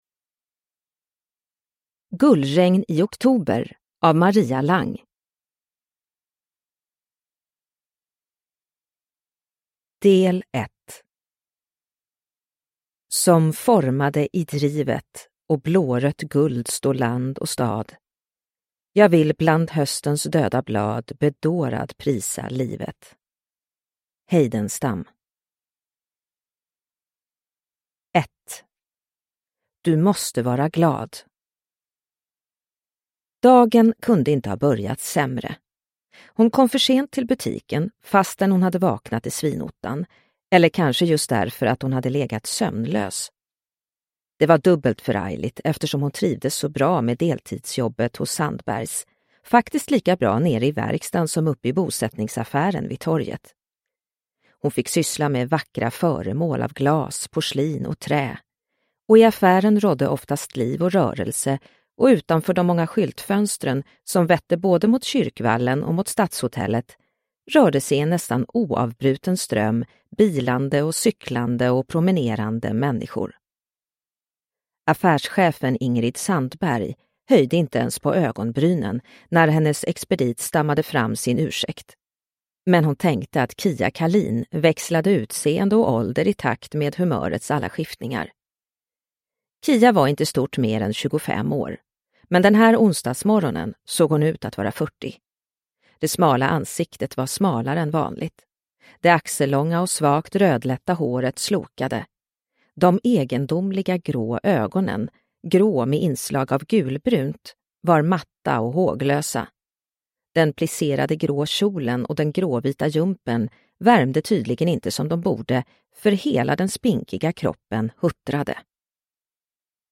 Gullregn i oktober – Ljudbok – Laddas ner